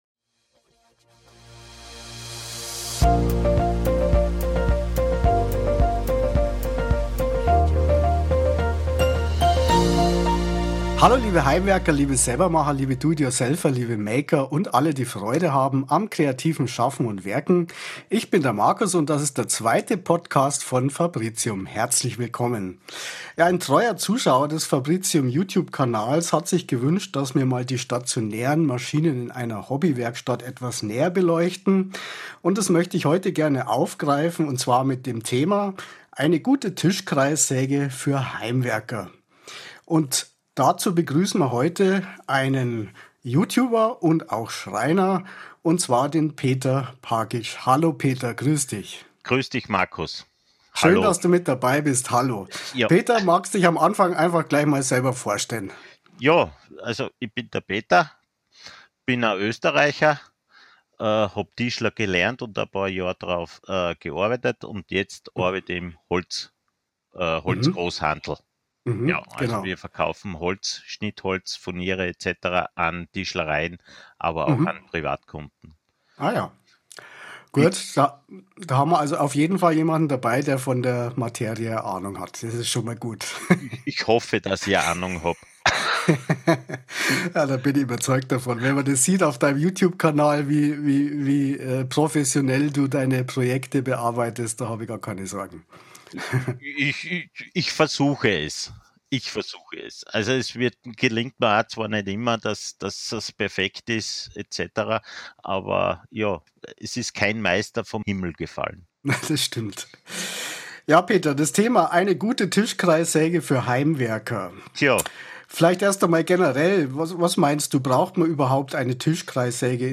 Fabricium im Dialog